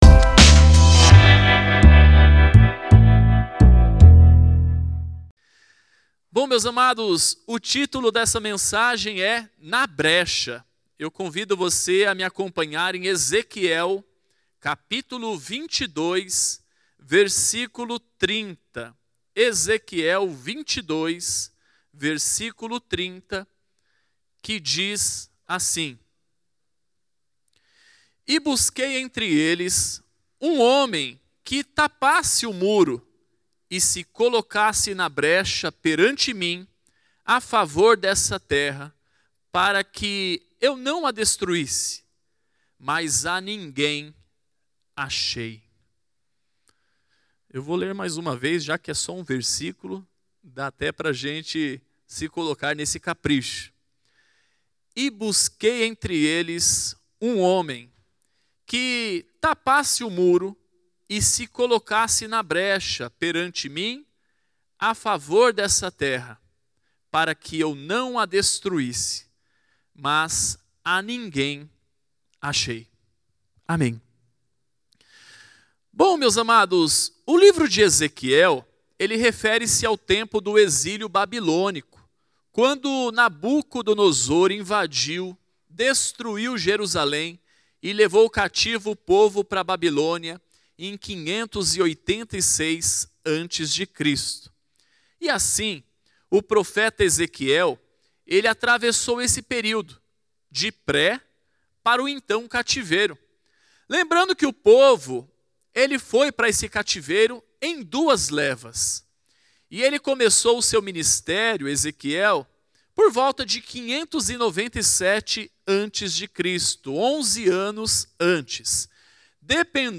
Mensagem ministrada